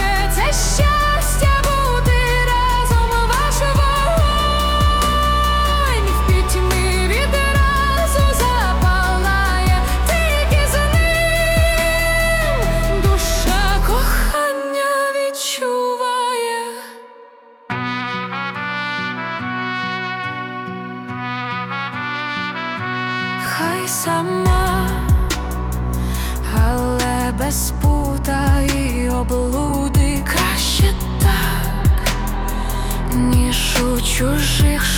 Жанр: Поп музыка / Украинские